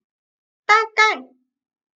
dàgài - ta cai Khoảng